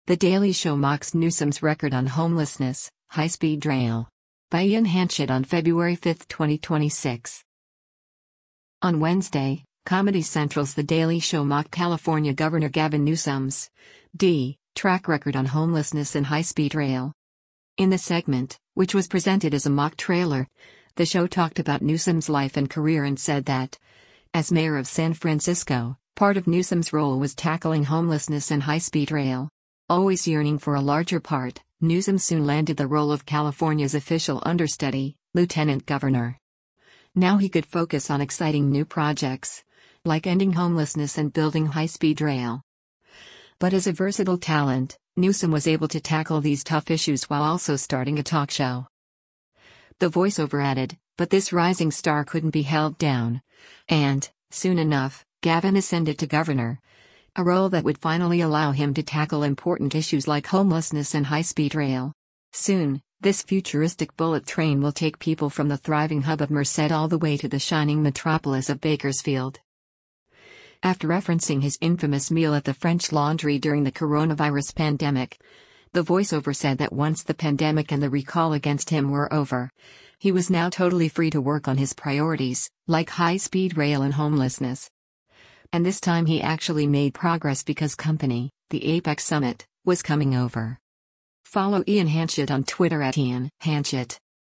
In the segment, which was presented as a mock trailer, the show talked about Newsom’s life and career and said that, as mayor of San Francisco, part of Newsom’s role was “tackling homelessness and high-speed rail. Always yearning for a larger part, Newsom soon landed the role of California’s official understudy, lieutenant governor. Now he could focus on exciting new projects, like ending homelessness and building high-speed rail.